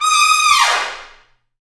Index of /90_sSampleCDs/Roland L-CDX-03 Disk 2/BRS_Tpts FX menu/BRS_Tps Falls